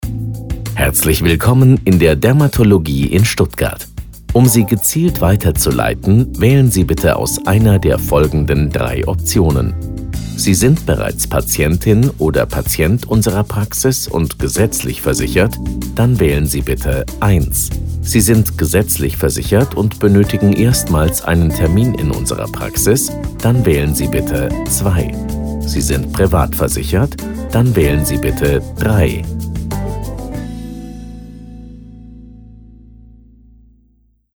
Telefonansagen mit echten Stimmen – keine KI !!!
IVR Telefonansage: (Weiterleitung) – Dermatologie Stuttgart